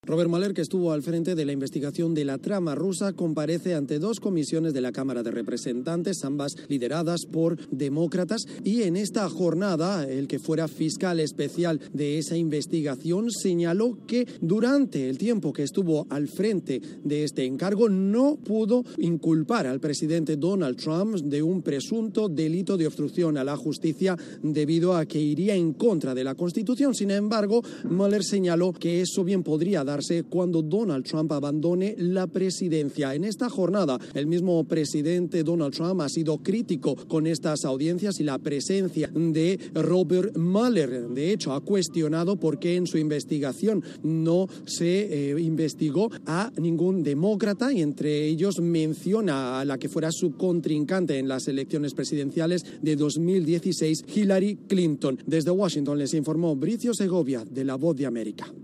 Robert Mueller testifica ante el Comité Judicial del Congreso el 24 de julio de 2019.